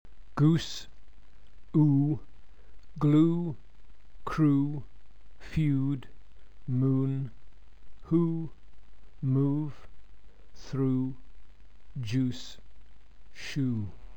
English tense vowels
GOOSE